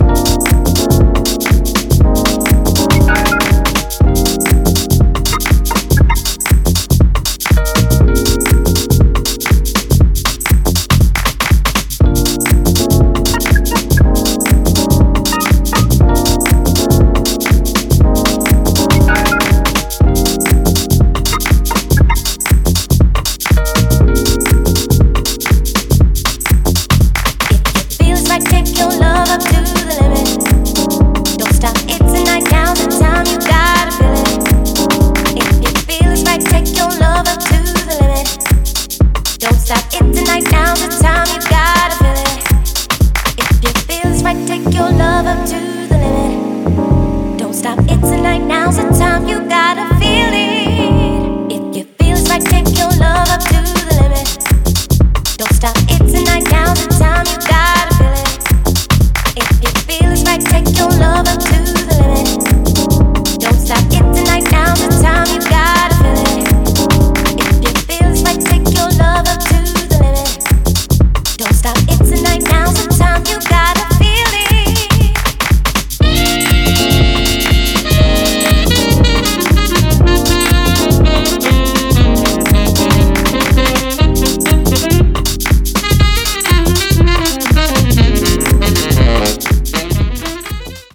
deep house, soul, and jazz-funk